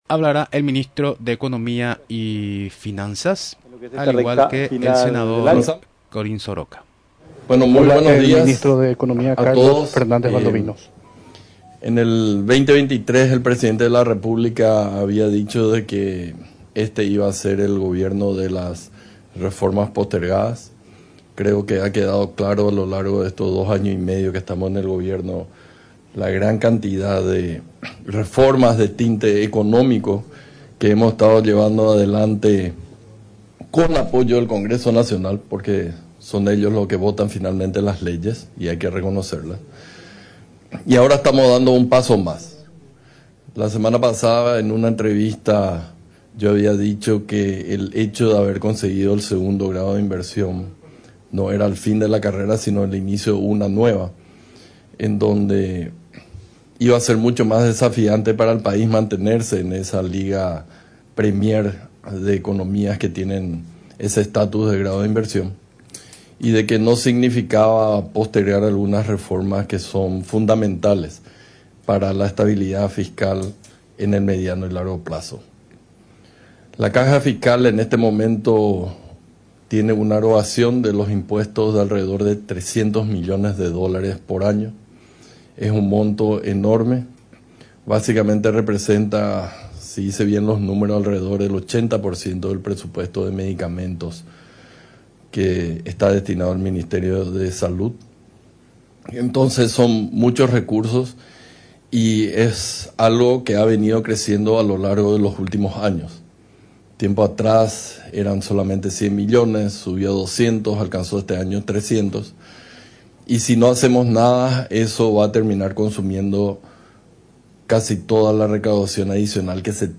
En conferencia de prensa en la residencia presidencial Mburuvicha Róga, el ministro de Economía y Finanzas, Carlos Fernández Valdovinos, explicó que actualmente la Caja Fiscal tiene una erogación anual de 300 millones de dólares, lo que consume gran parte de los ingresos adicionales que tiene el Estado.